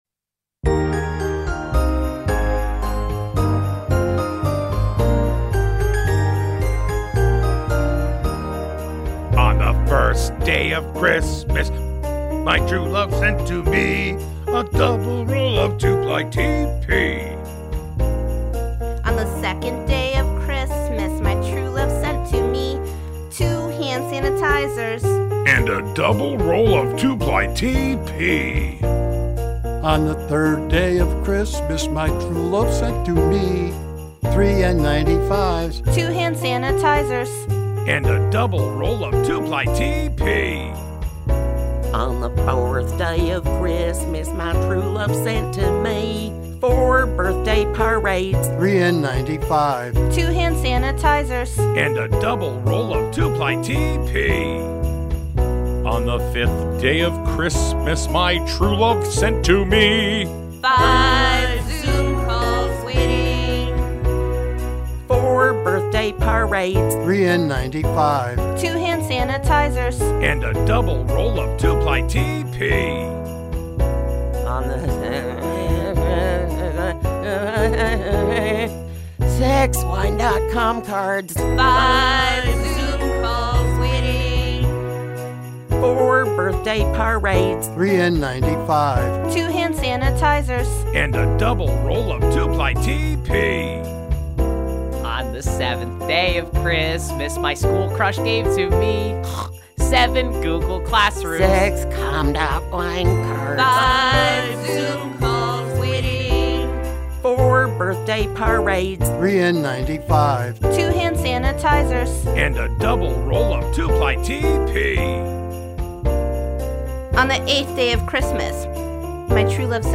Last week, most of the staff got together and did a parody to the 12 Days of Christmas, and we put our own spin on it.